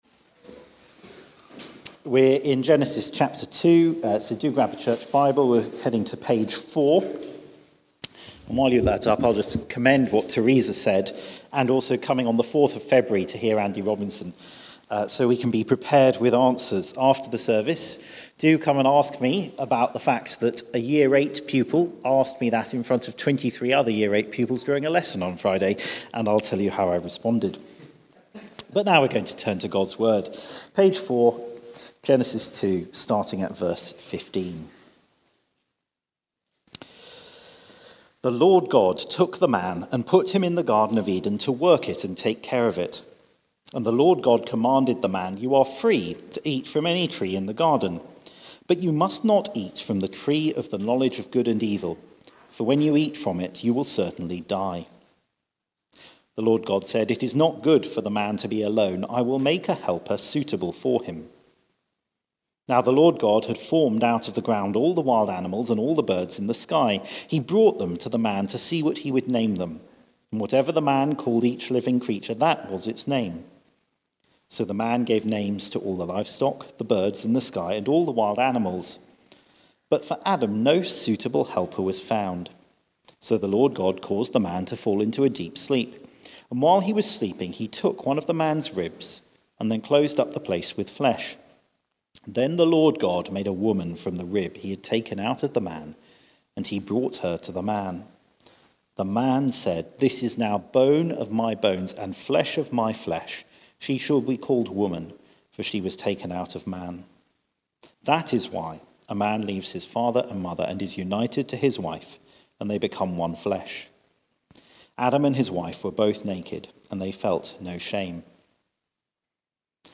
Church at the Green Sunday 4pm
Theme: God’s plan for humanity 2 Sermon